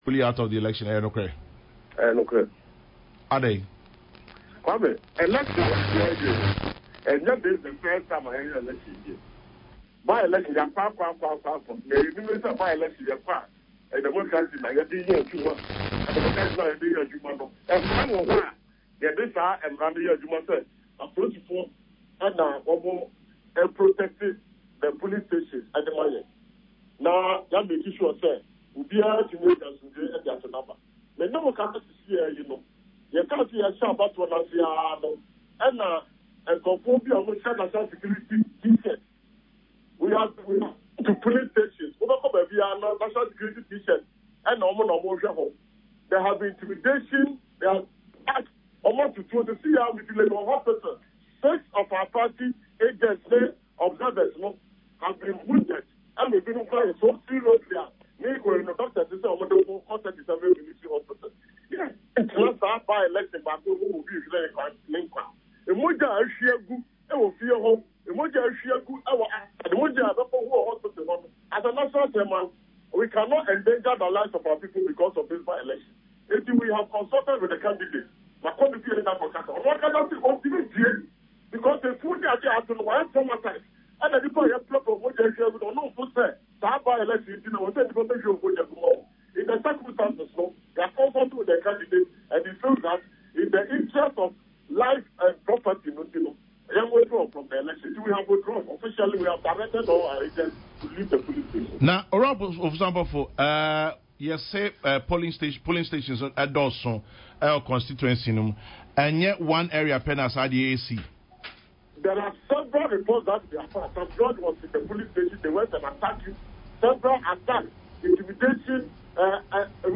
National Chairman of the NDC, Samuel Ofosu Ampofo, confirmed the news in an interview with Kwami Sefa Kayi on Peace FM‘s “Kokrokoo”